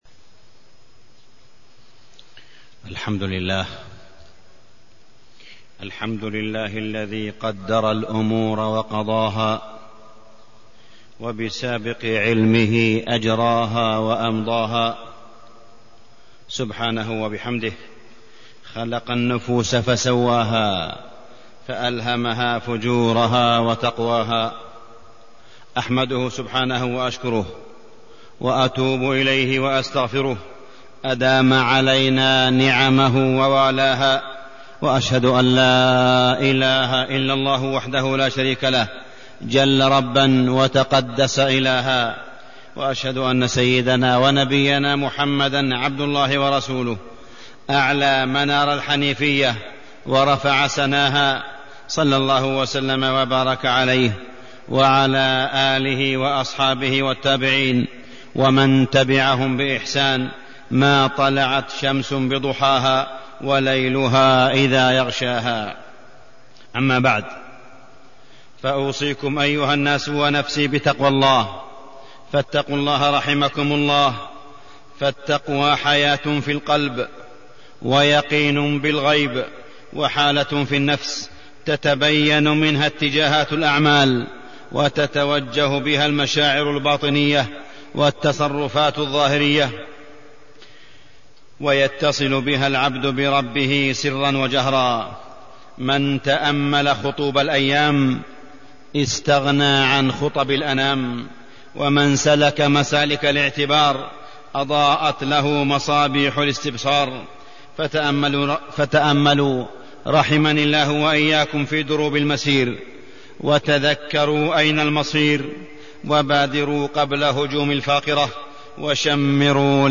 تاريخ النشر ١٢ ربيع الأول ١٤٢٣ هـ المكان: المسجد الحرام الشيخ: معالي الشيخ أ.د. صالح بن عبدالله بن حميد معالي الشيخ أ.د. صالح بن عبدالله بن حميد الإيمان بالغيب